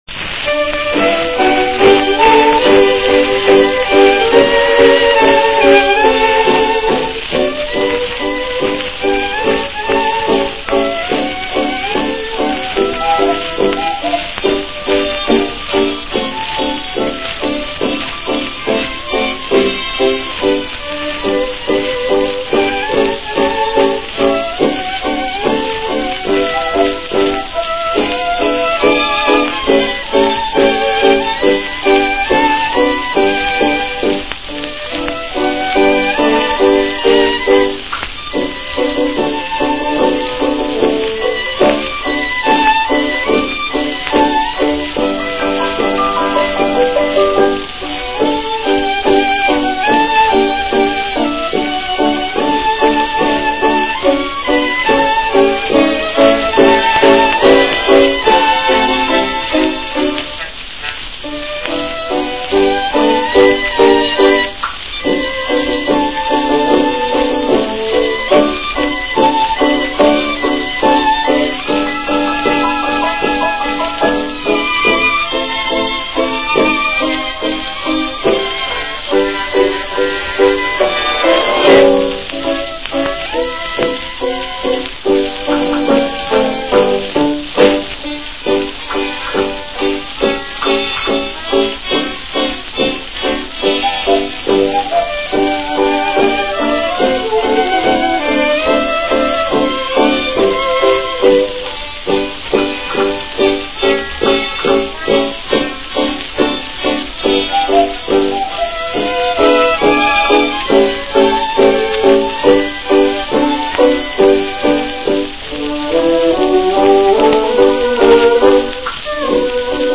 ein Blues
Tanzmusik 20er Jahre